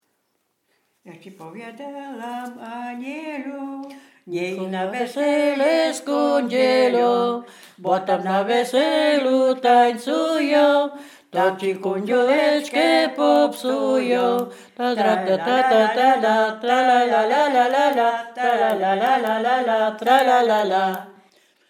województwo dolnośląskie, powiat lwówecki, gmina Lwówek Śląski, wieś Zbylutów
Weselna
przyśpiewki tajdany do tańca